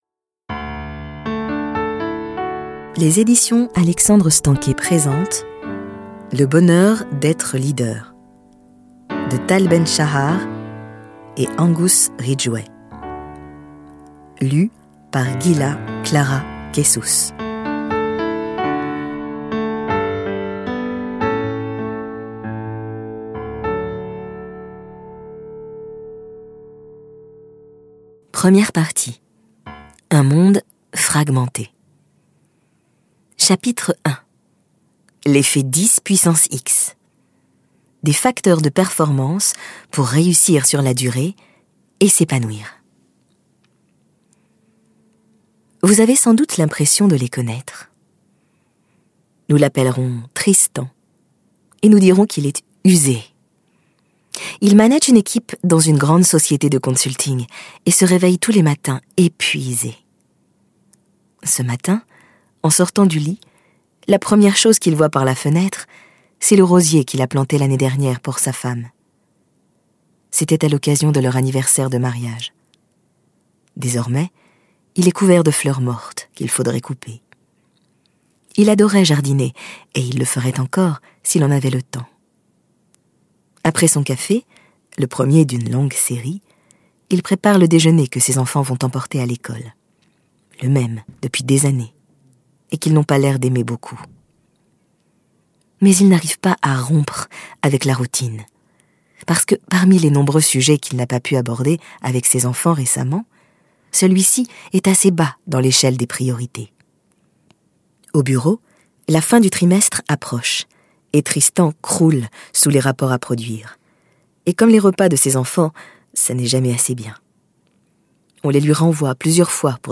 Lire un extrait - Le bonheur d'être leader de Tal BEN-SHAHAR, Angus Ridgway